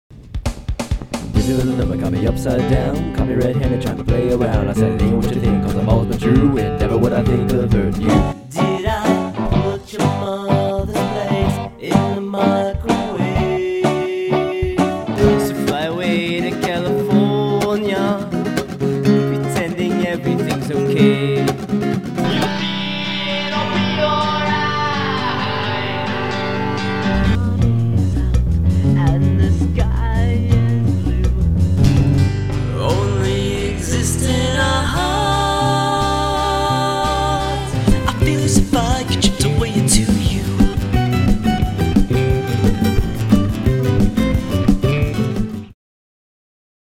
To illustrate my humble beginnings as a songwriter, I’ve created a montage of some of my bad news songwriting from years past.[1]
Okay, humor aside, the primary problem with those songs, in my opinion, is that I’m singing poorly written lyrics with a poorly executed vocal line.